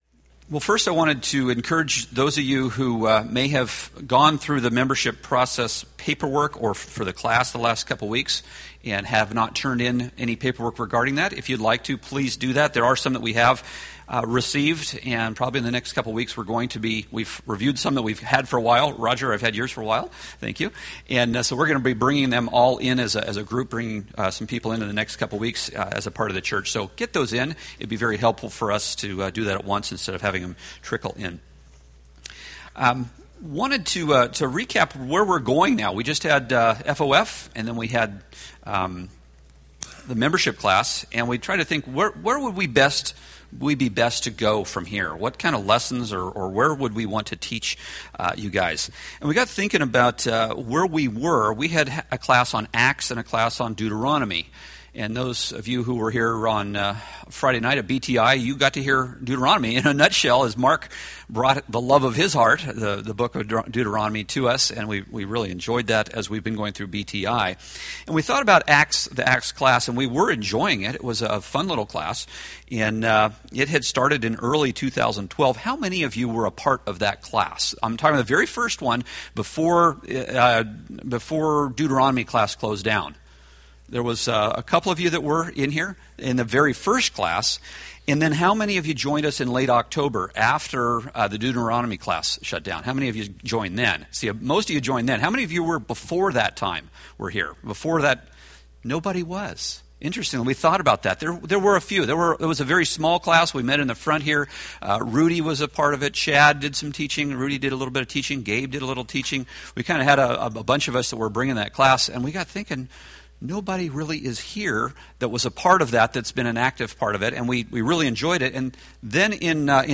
Date: Oct 27, 2013 Series: Acts Grouping: Sunday School (Adult) More: Download MP3